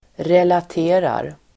Uttal: [relat'e:rar]